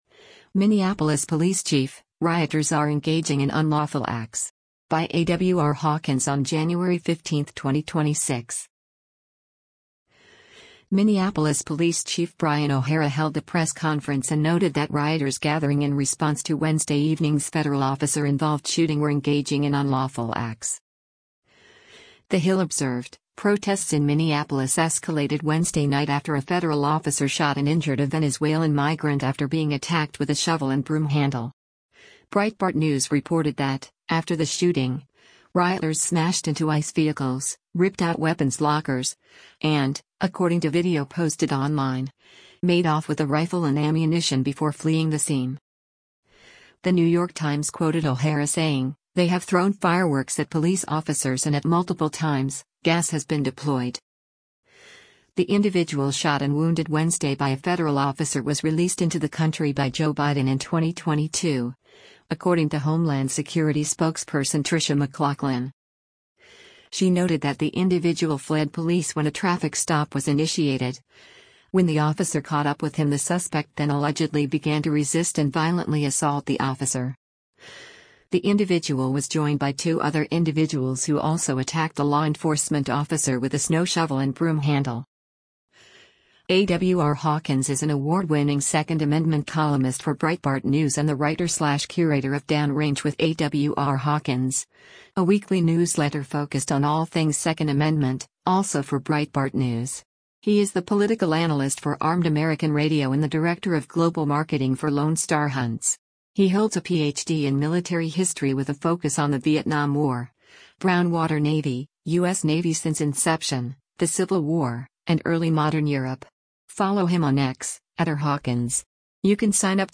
Minneapolis Police Chief Brian O'Hara speaks during a press conference at the Departm